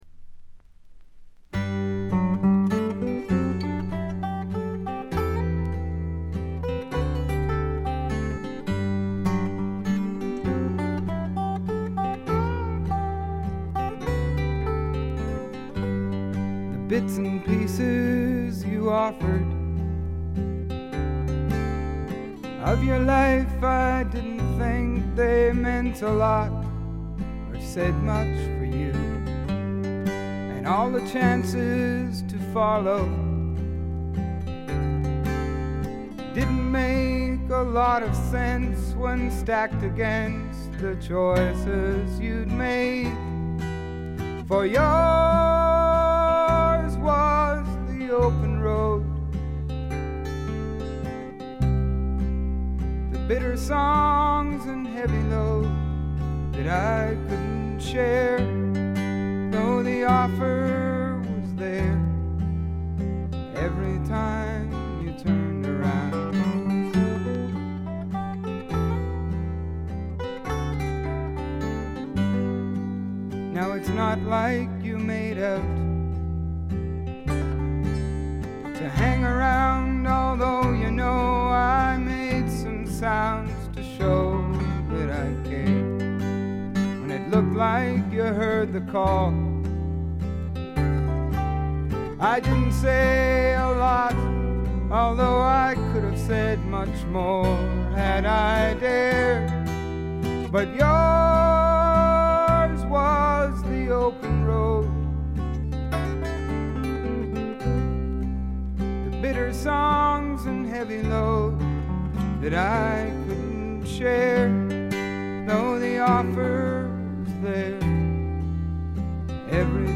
ところどころでチリプチ。
曲が素晴らしくよくできていてバックの演奏もシンプルで実に的確。
試聴曲は現品からの取り込み音源です。
Vocals, Acoustic Guitar